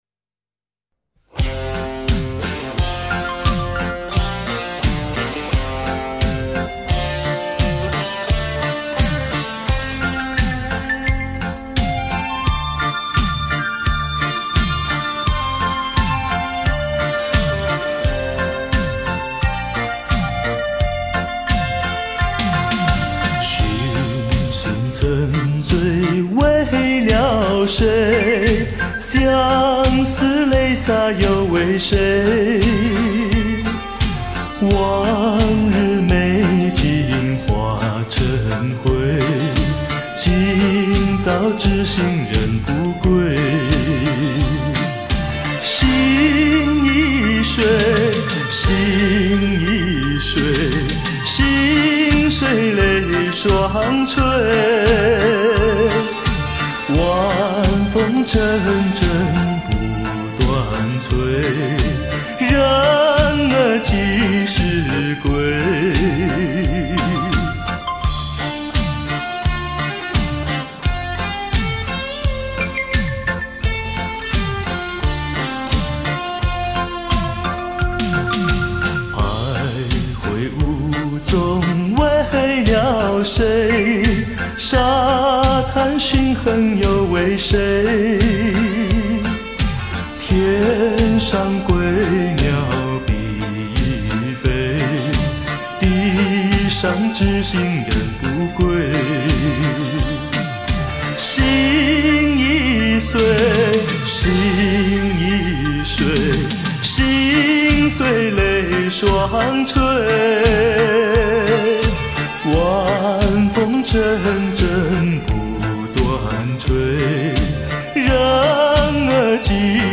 样本格式    : 44.100 Hz; 16 Bit; 立体声